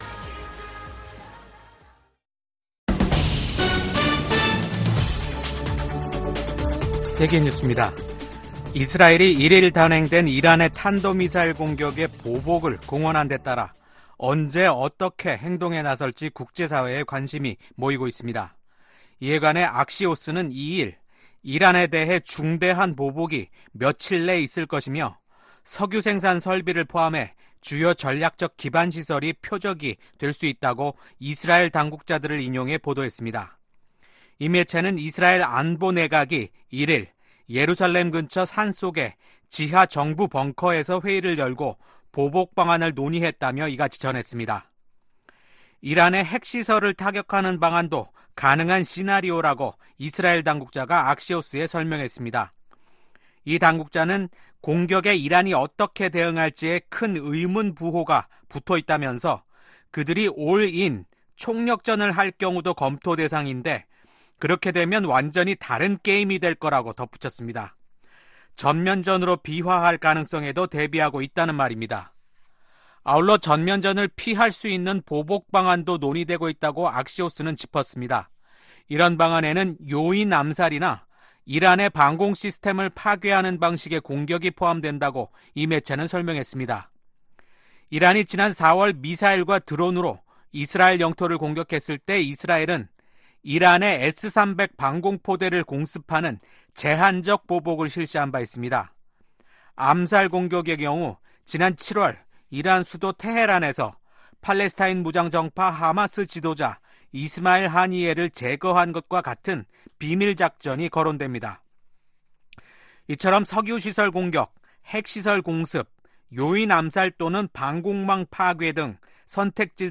VOA 한국어 아침 뉴스 프로그램 '워싱턴 뉴스 광장' 2024년 10월 3일 방송입니다. 민주당의 팀 월즈 부통령 후보와 공화당의 J.D. 밴스 후보가 첫 TV 토론에서 안보와 경제, 이민 문제를 두고 첨예한 입장 차를 보였습니다. 토니 블링컨 미국 국무장관은 북한, 중국, 러시아, 이란을 국제 질서를 흔드는 세력으로 규정하며 동맹과 단호히 대응해야 한다고 밝혔습니다.